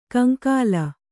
♪ kaŋkāla